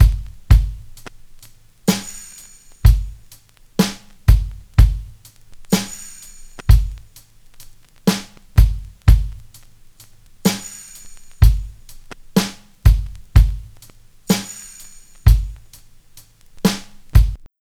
56BRUSHBT2-R.wav